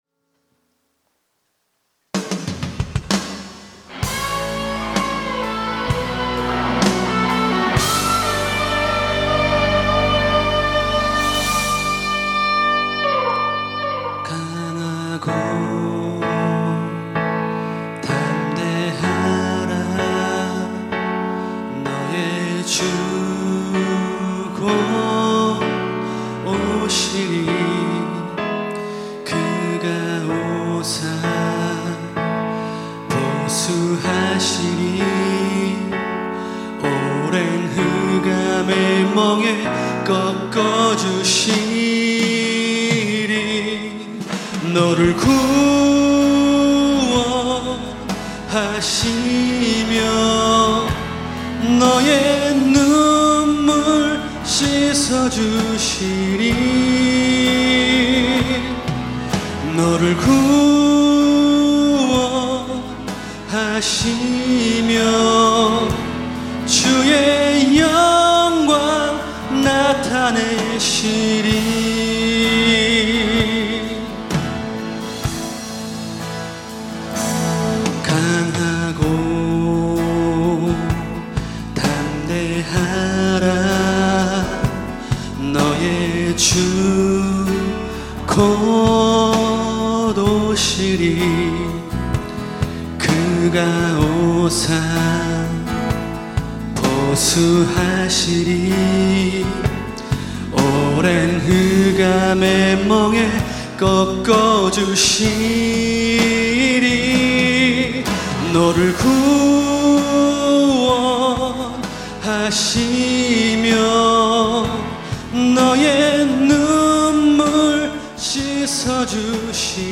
특송과 특주 - 강한 용사